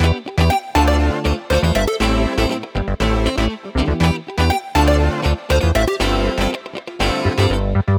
23 Backing PT2.wav